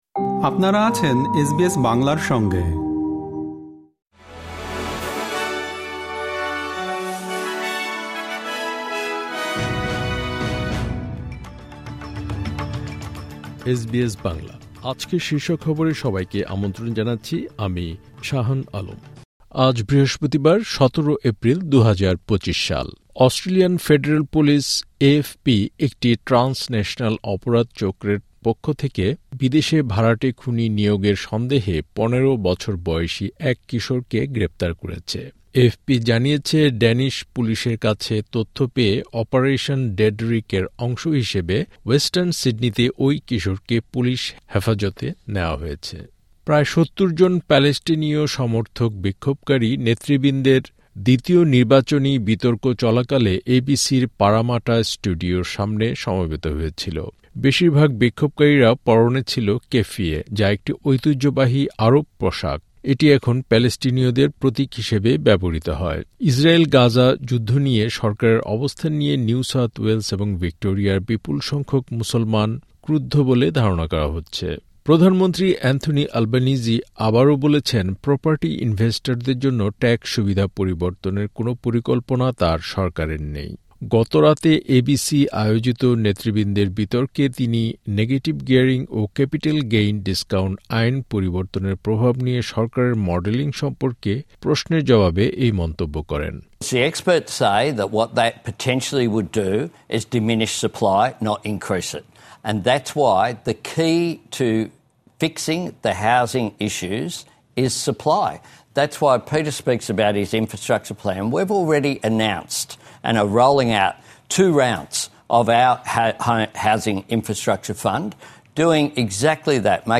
এসবিএস বাংলা শীর্ষ খবর: ১৭ এপ্রিল, ২০২৫